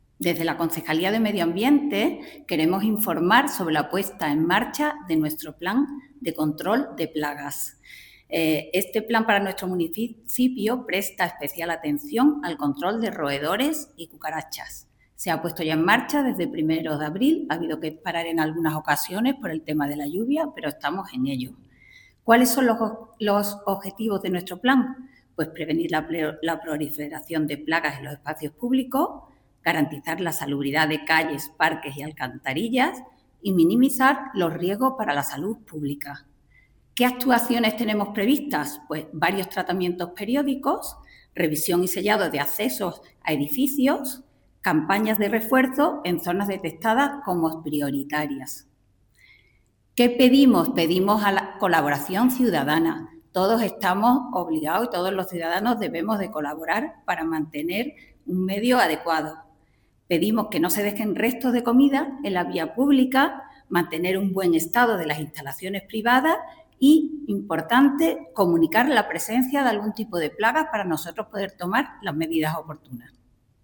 La concejal delegada de Medio Ambiente, María Luisa Enrile, informa que esta campaña se encuentra ya en desarrollo desde comienzos del mes de abril, aunque ha sufrido algunas interrupciones puntuales debido a las condiciones meteorológicas por lluvia.
Cortes de voz